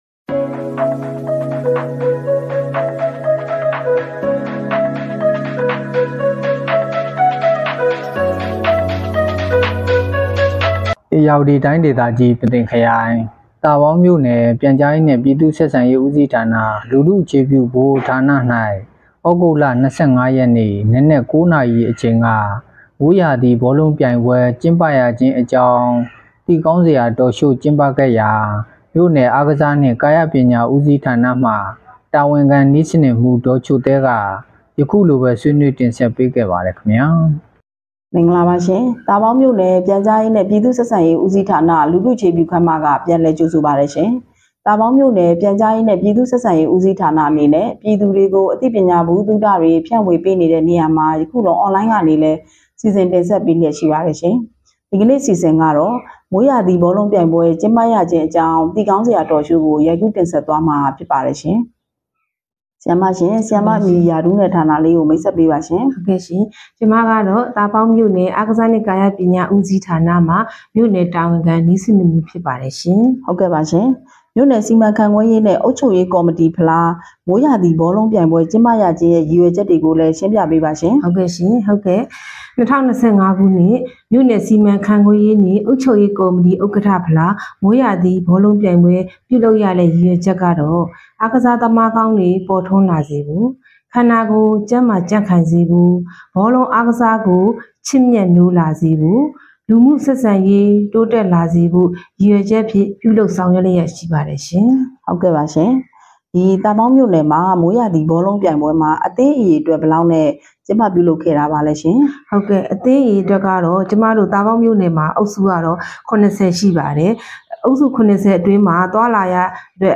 လူထုအခြေ ပြုဗဟိုဌာနတွင် မိုးရာသီဘောလုံးပြိုင်ပွဲကျင်းပရခြင်းအကြောင်းသိကောင်းစရာ Talk show ကျင်းပ သာပေါင်း သြဂုတ် ၂၆ ပေးပို့သူ